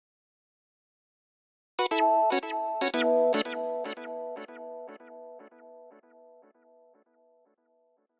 11 Solo Synth PT3.wav